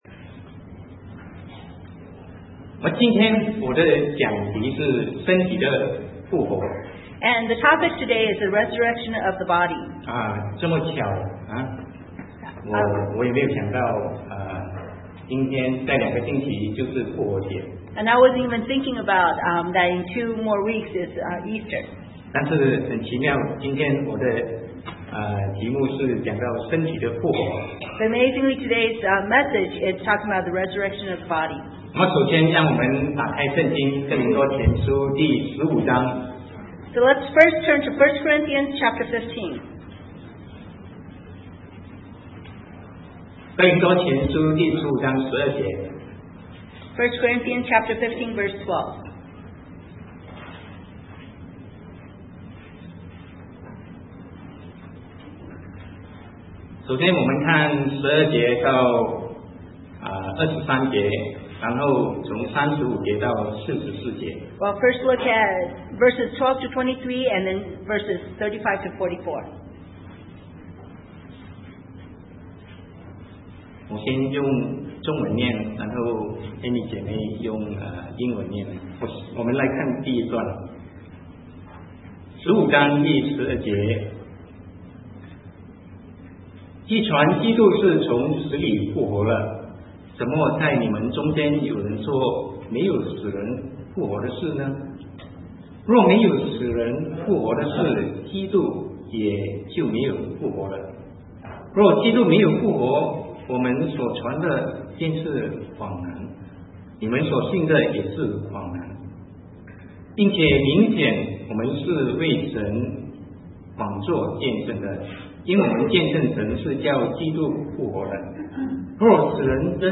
Sermon 2010-03-21 The Resurrection of the Body